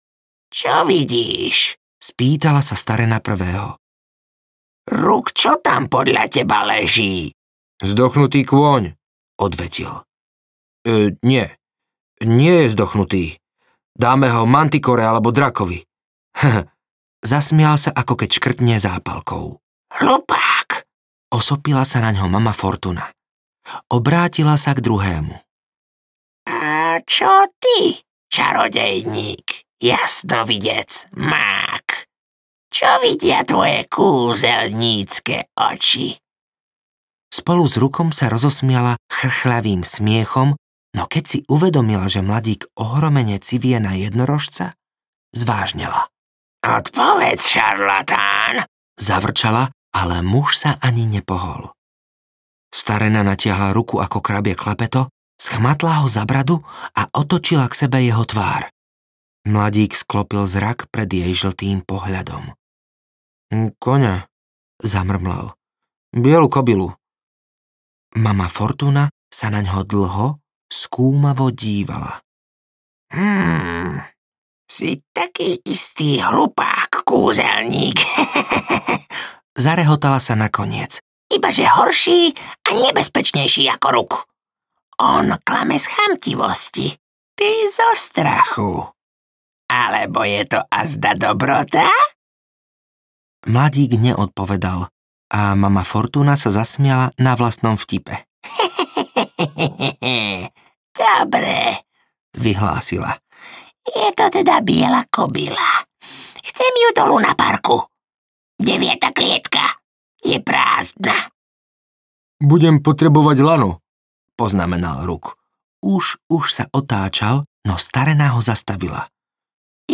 Posledný jednorožec audiokniha
Ukázka z knihy